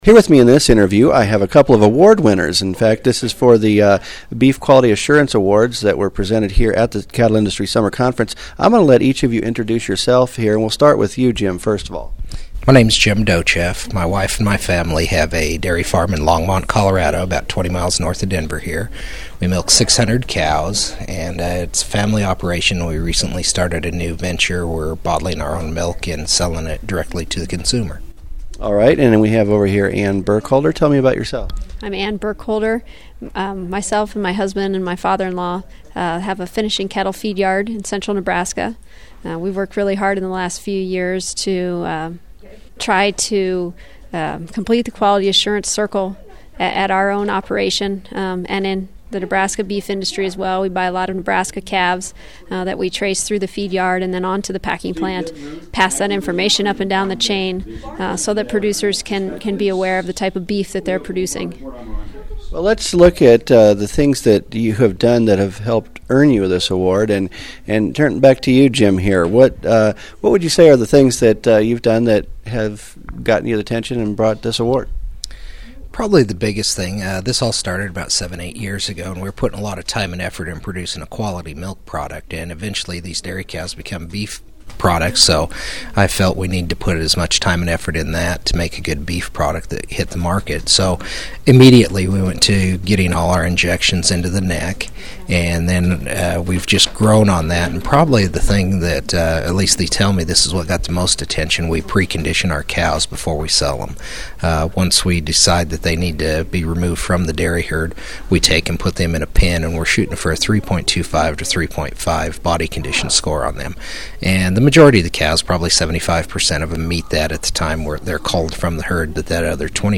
Ag Groups, Audio, Beef, Cattle Industry Conference